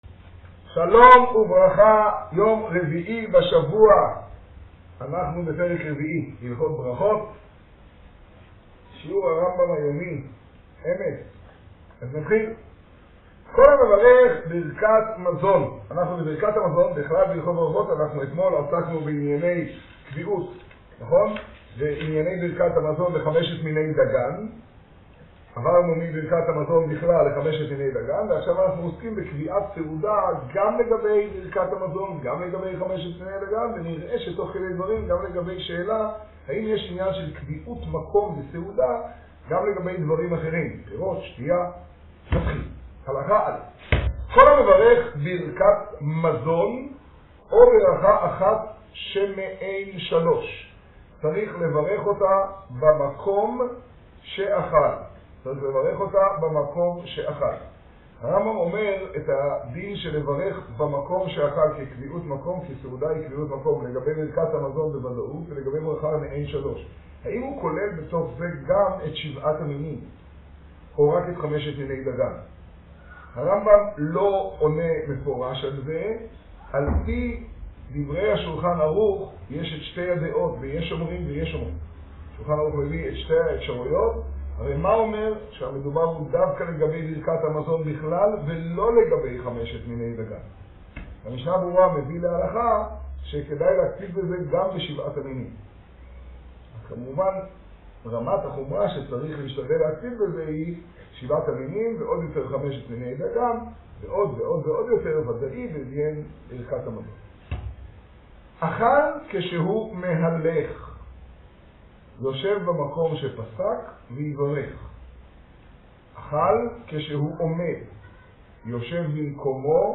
השיעור במגדל, כח אדר תשעה.